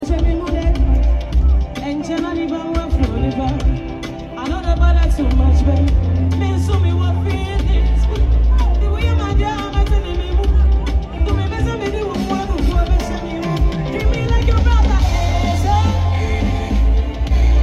live on stage